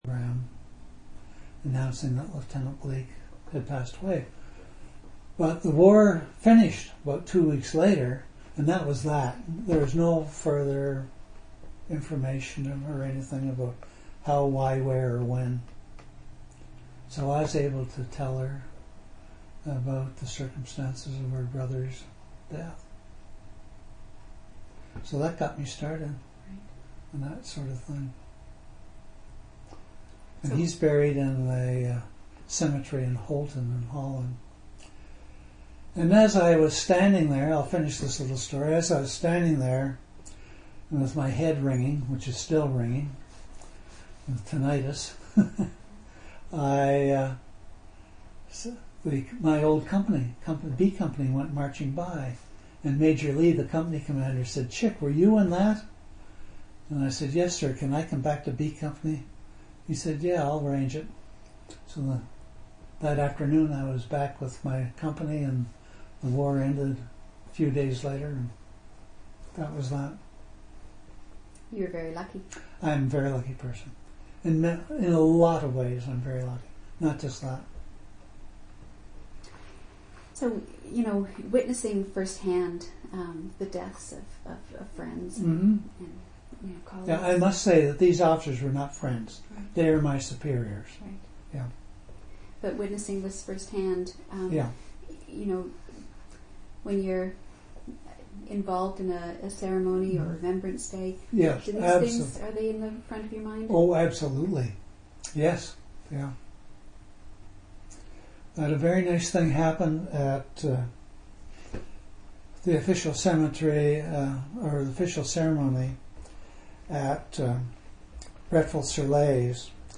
oral histories (literary genre) sound recordings interviews reminiscences